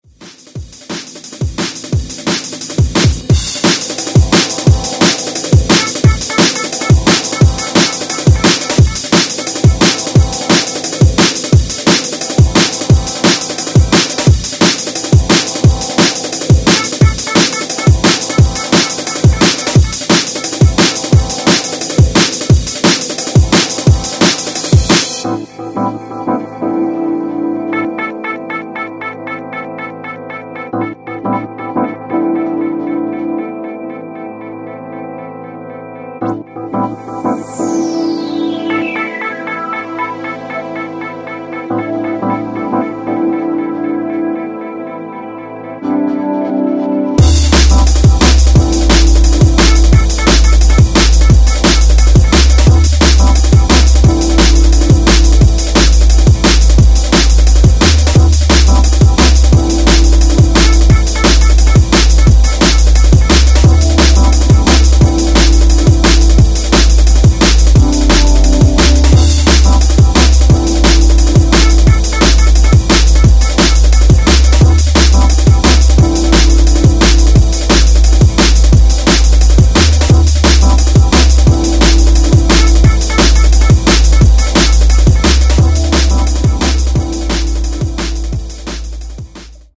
Styl: Drum'n'bass